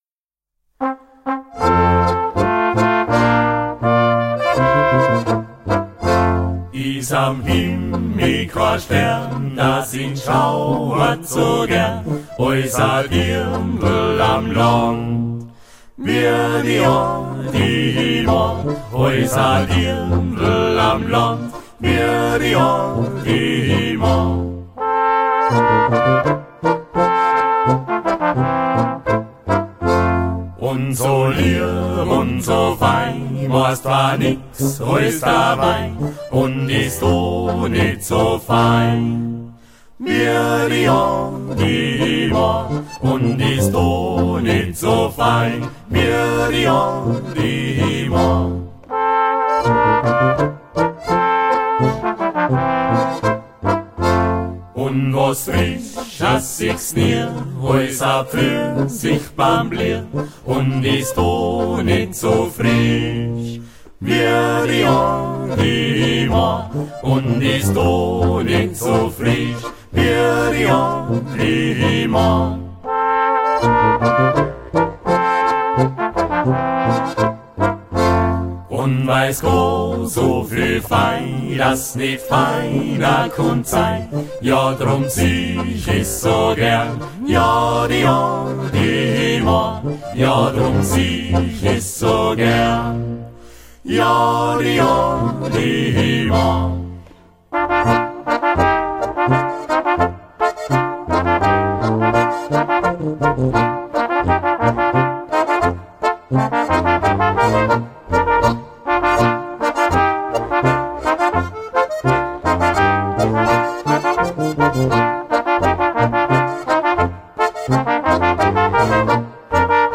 Genre: Volksmusik.